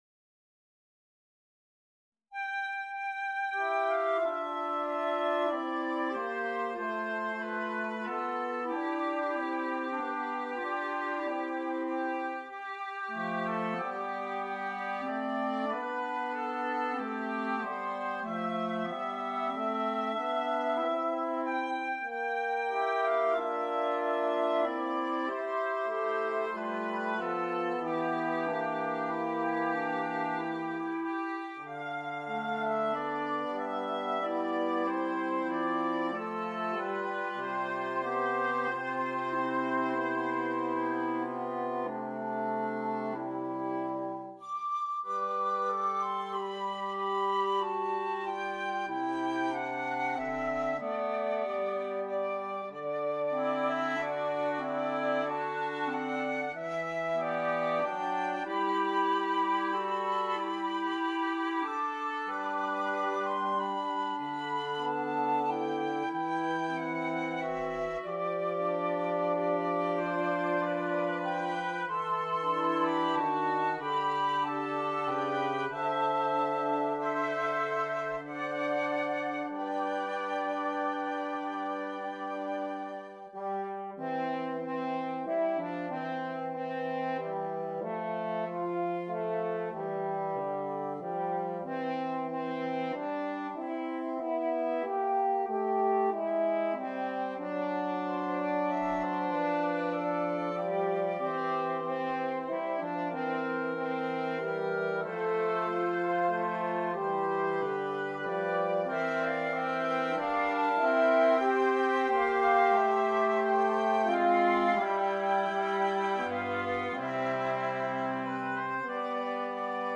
Woodwind Quintet
Traditional